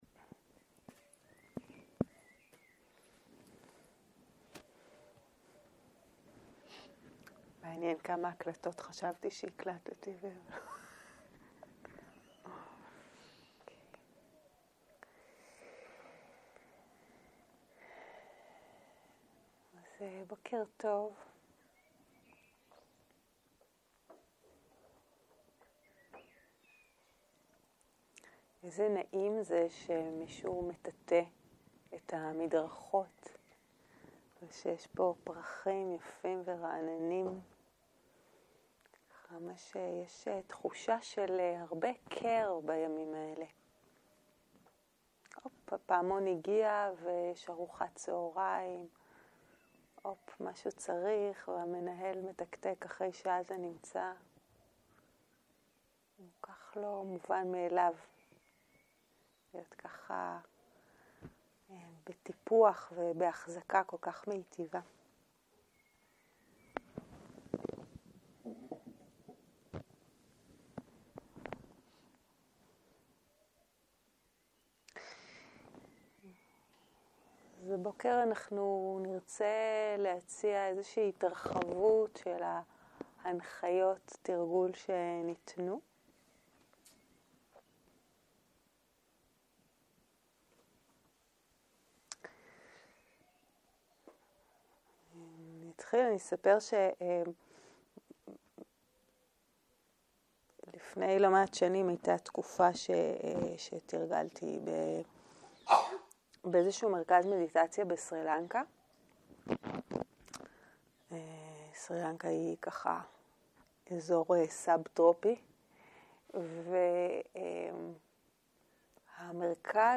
07.03.2023 - יום 5 - בוקר - הנחיות מדיטציה - תשומת לב פתוחה - הקלטה 11